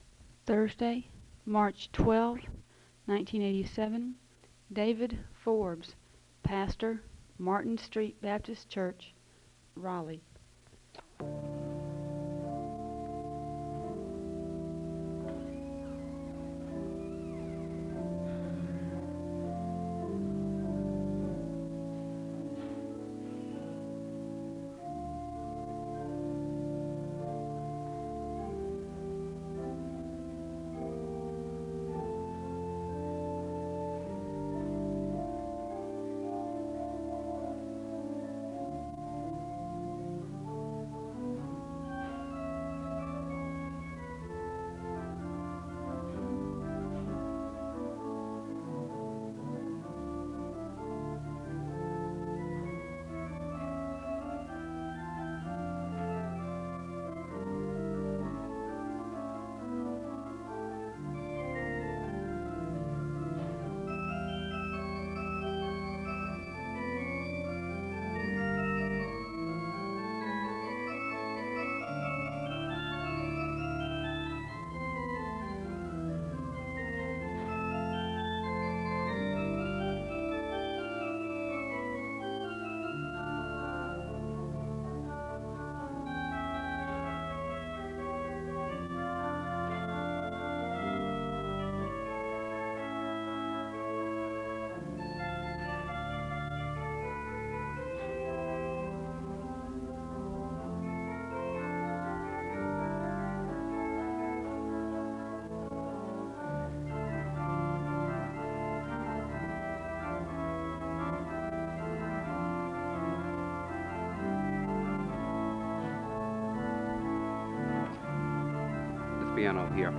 The service begins with organ music (0:00-6:54). There is a call to worship and a word of prayer (6:55-8:00)....
There is a responsive reading (10:39-12:02). There is a Scripture reading from John 12 (12:03-13:22).
There is a song of worship (16:16-19:43).
The service concludes in a moment of prayer (40:56-41:55).